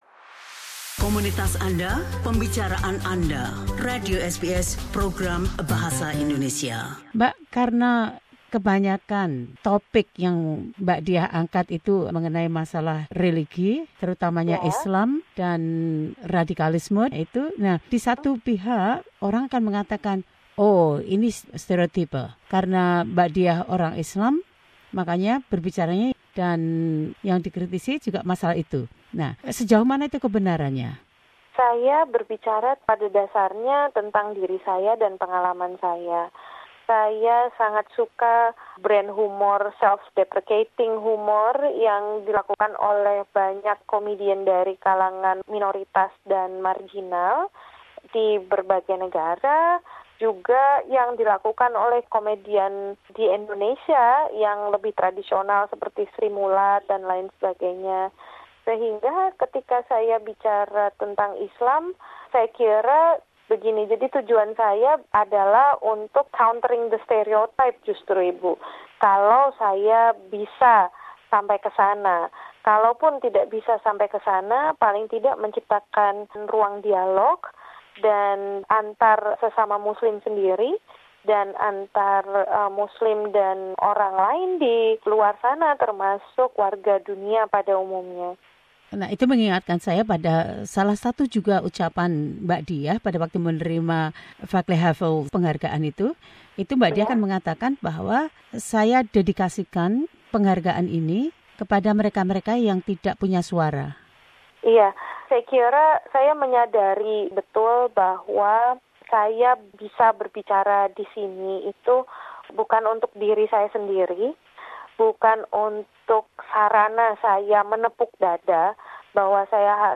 Comic Sakdiyah Maruf continues her conversation about life and comedy.